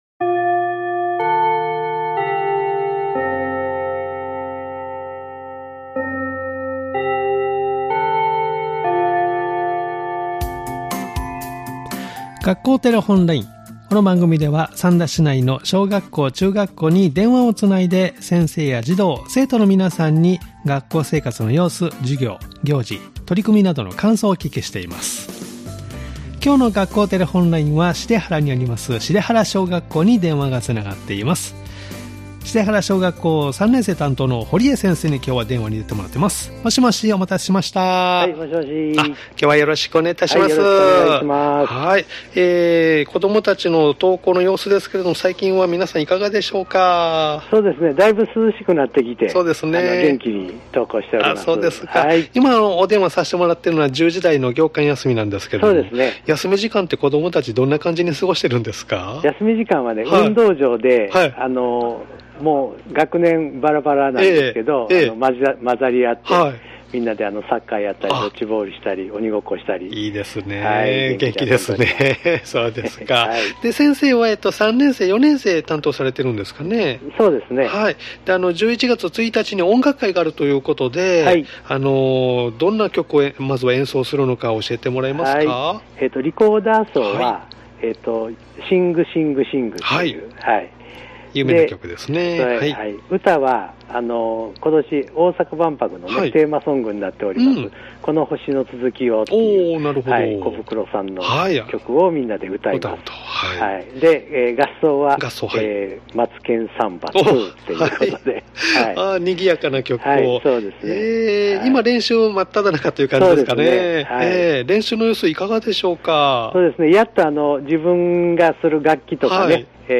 （再生ボタン▶を押すと番組が始まります） 「学校テレフォンライン」では三田市内の小学校、中学校に電話をつないで、先生や児童・生徒の皆さんに、学校生活の様子、授業や行事、取り組みなどの感想をお聞きしています！